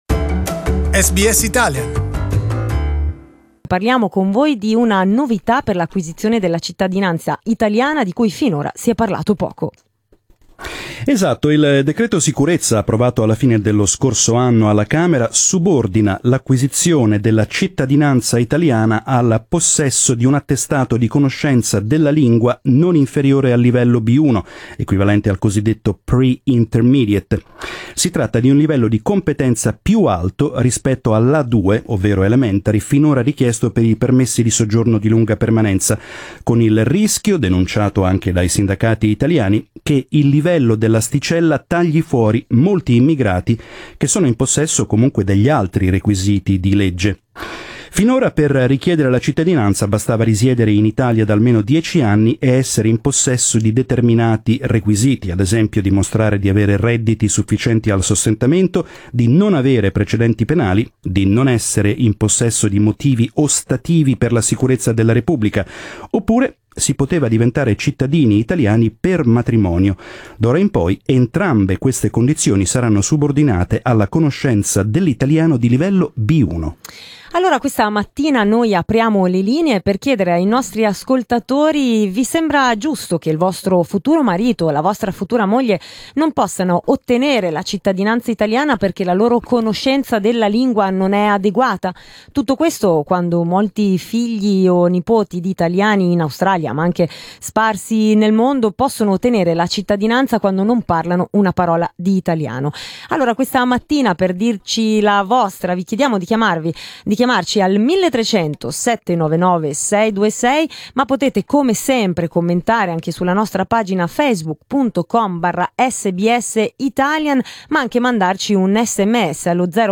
Talkback: Italian language test for citizenship
In our program, we asked our listeners what they thought about the changes.